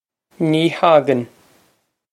Pronunciation for how to say
Nee hog-on.
This is an approximate phonetic pronunciation of the phrase.